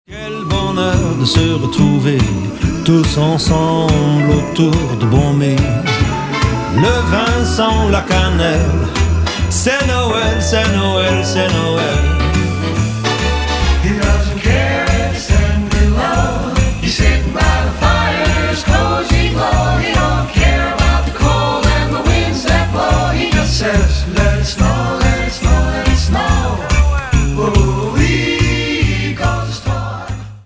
French-language replica
Recorded in 2020, but sounds like the late 40s!